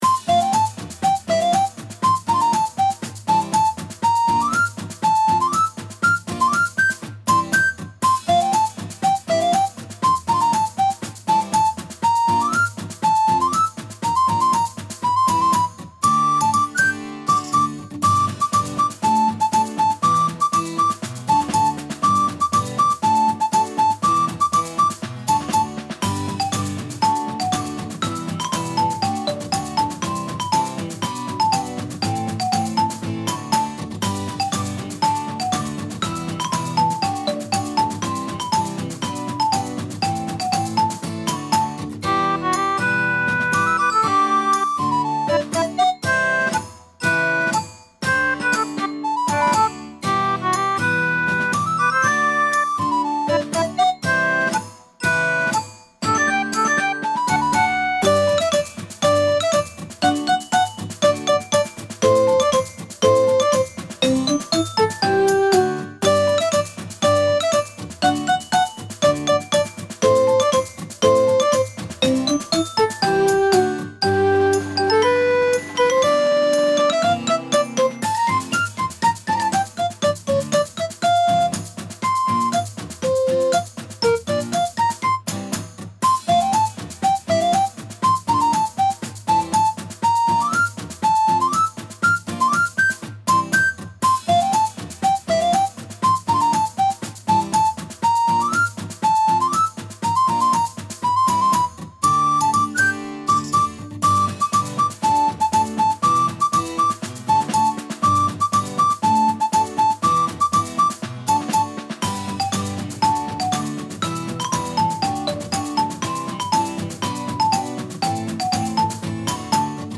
リコーダーやマリンバ、鍵盤ハーモニカなどが奏でるおもちゃな雰囲気の可愛いBGMです。